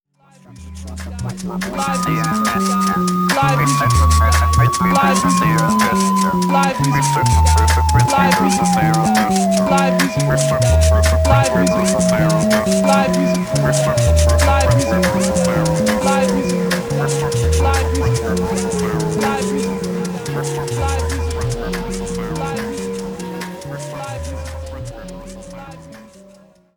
Adding this more than slightly over the top ring mod from some messing around on another take.